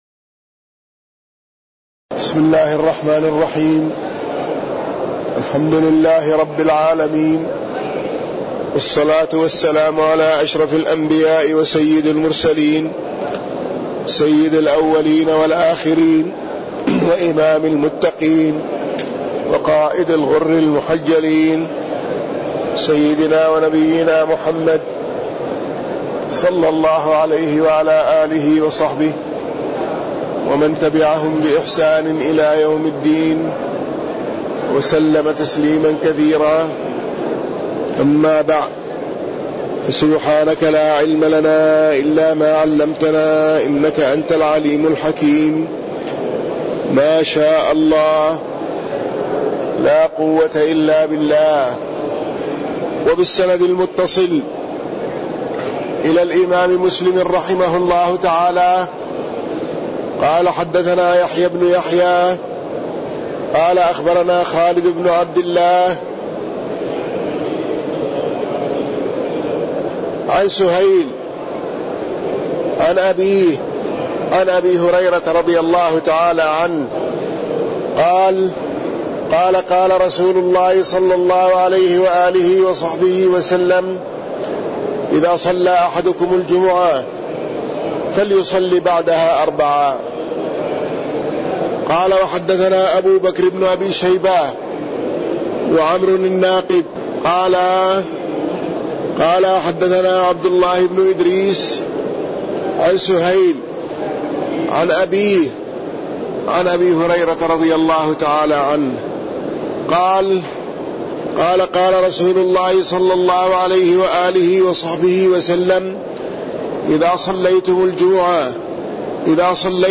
تاريخ النشر ١٤ رمضان ١٤٣١ هـ المكان: المسجد النبوي الشيخ